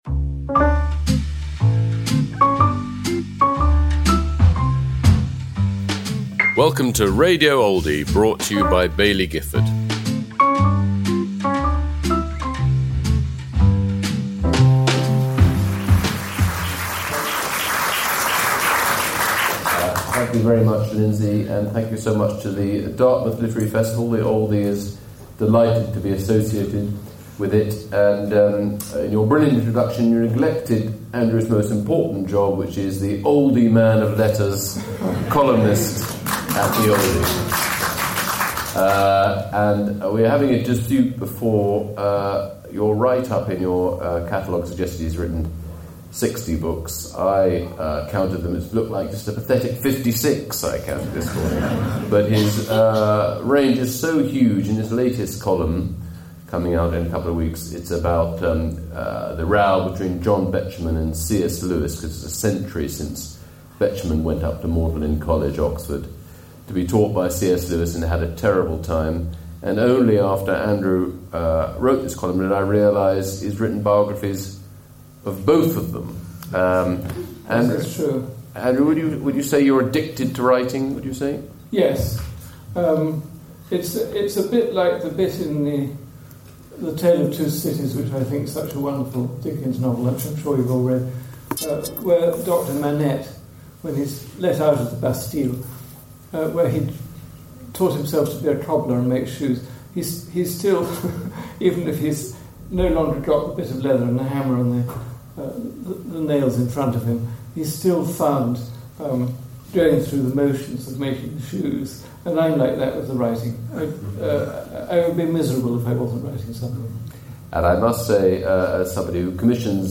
A N Wilson in conversation with Harry Mount at this month's Dartmouth Literary Festival, sponsored by The Oldie.